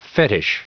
Prononciation du mot fetich en anglais (fichier audio)
Prononciation du mot : fetich